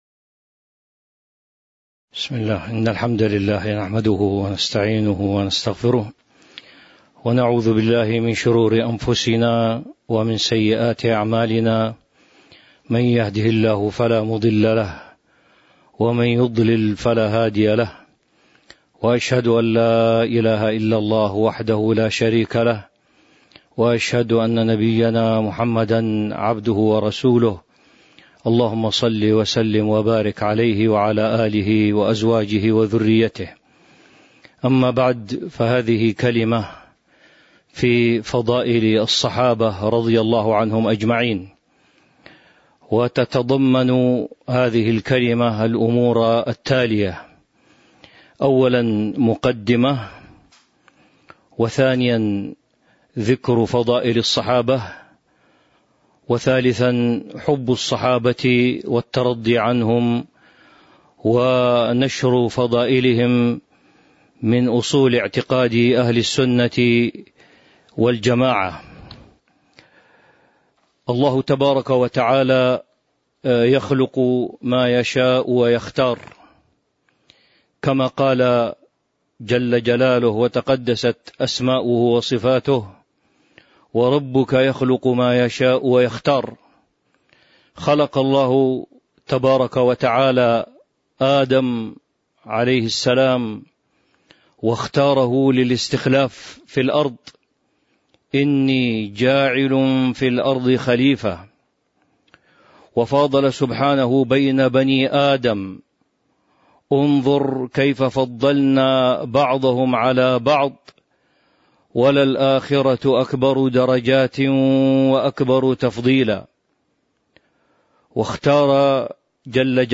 تاريخ النشر ١٢ صفر ١٤٤٢ هـ المكان: المسجد النبوي الشيخ